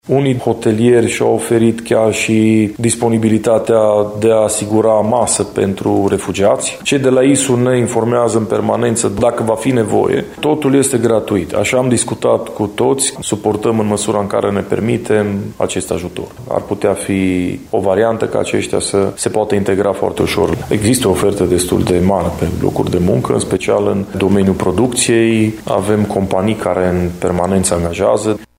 Primarul municipiului, Iulian Sîrbu, spune că proprietarii unităților de cazare s-au arătat dispuși să ofere și masă pe lângă cazare, chiar dacă domeniul HoReCA din Sighișoara traversează o perioadă foarte grea.